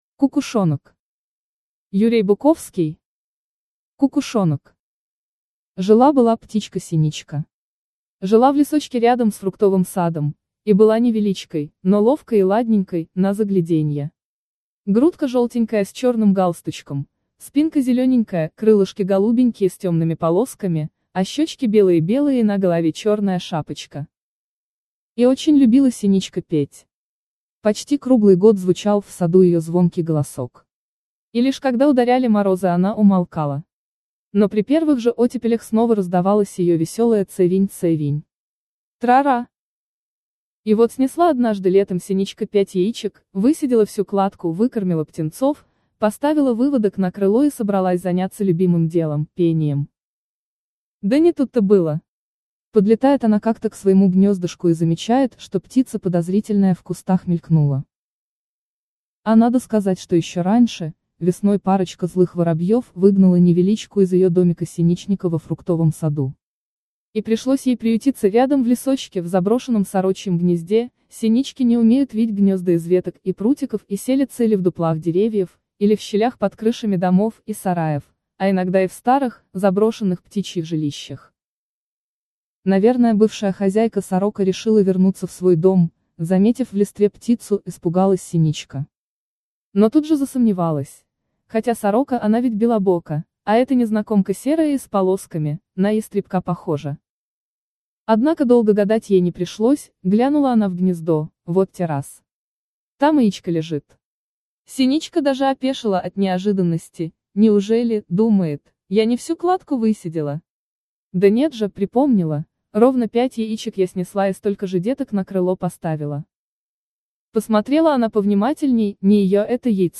Аудиокнига Кукушонок. Сказка | Библиотека аудиокниг